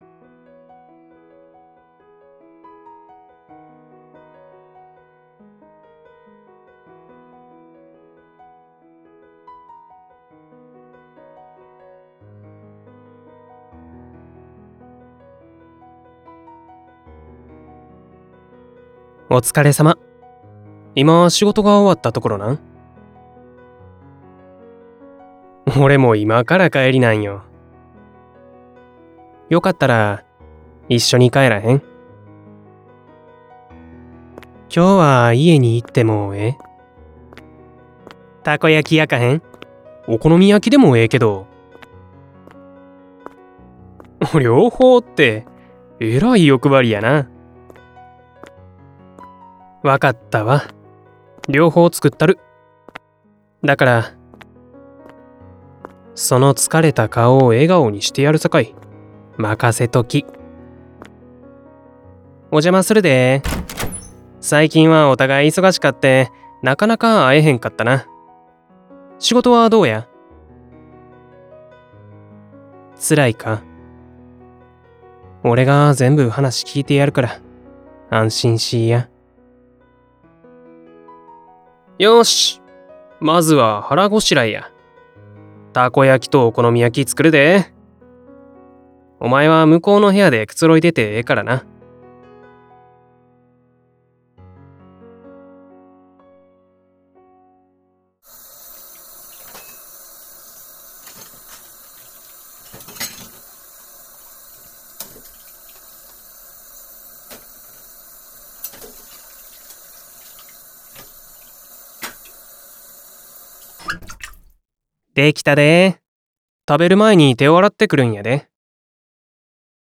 関西弁彼氏
関西弁彼氏.wav